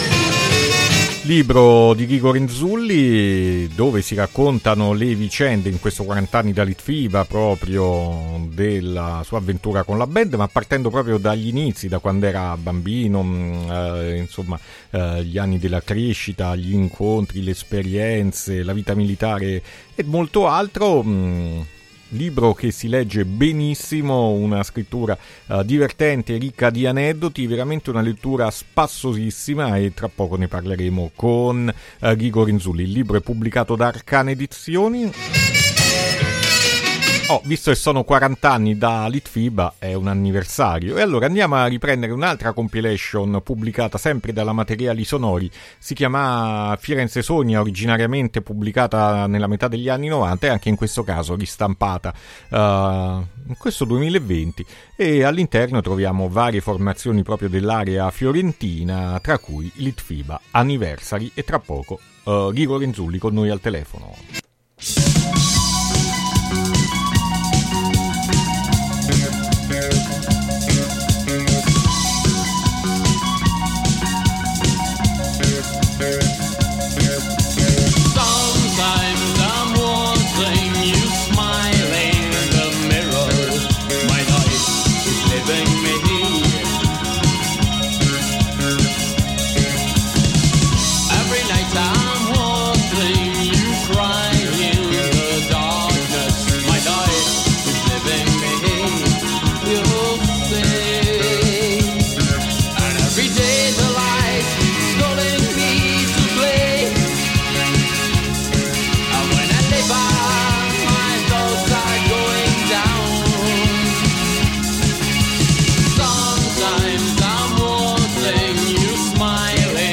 Intervista Ghigo Renzulli per 40 anni da Litfiba | Radio Città Aperta